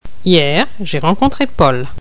Accent démarcatif
Placé en fin de mot ou de syntagme et permettant de délimiter les différentes unités d'un énoncé